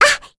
May-Vox_Attack2.wav